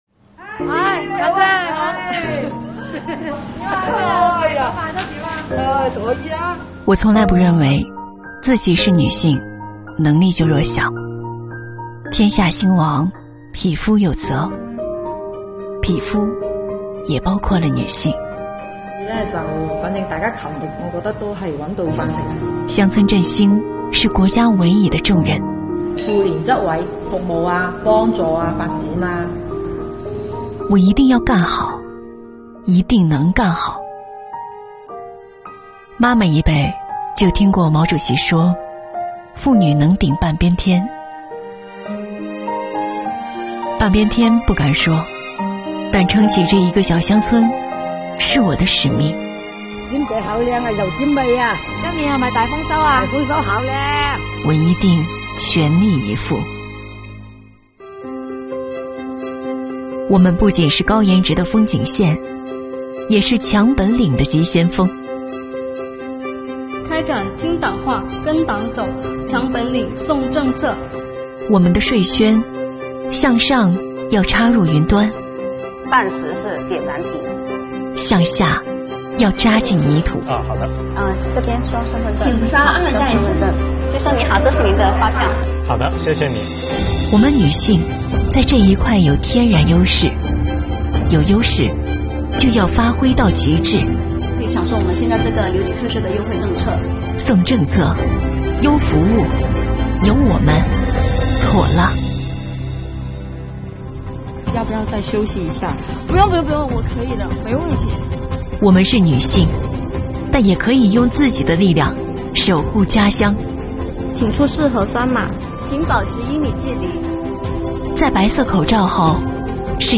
本作品巧妙地运用三个汉字，将三种具有代表性的女性故事和女性力量展现出来，短片中真诚的旁白和真实的人物故事，让人们看见女性力量，致敬巾帼税务人。